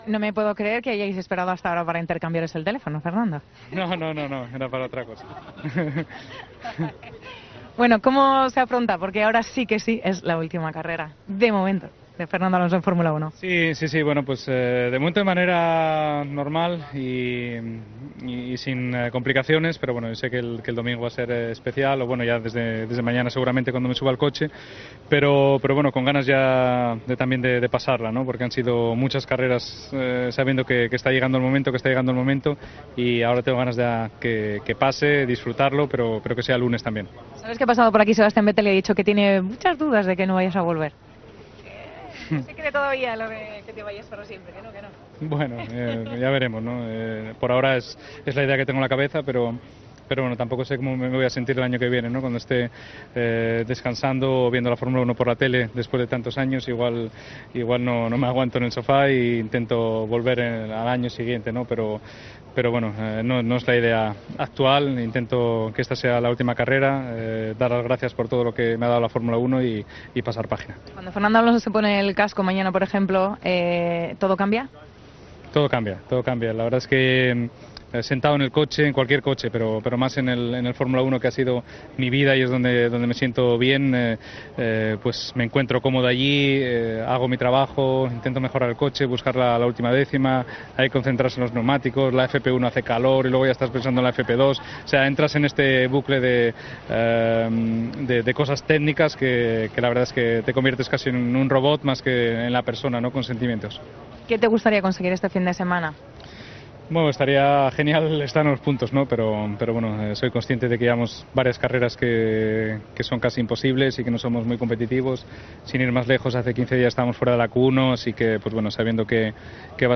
"Estoy muy orgulloso de lo que conseguí durante mi carrera en la F1, pero sobre todo por el impacto causado en España y en mi región, en Asturias, por toda la gente que no lo había hecho antes y comenzó a seguir la F1. Y por toda la gente que se acerca a Oviedo al museo. Son cosas que me han hecho sentirme muy orgulloso", contestó a Efe el genial piloto asturiano durante la conferencia de prensa oficial de la FIA (Federación Internacional del Automovilismo) que tuvo lugar este jueves en el circuito de Yas Marina.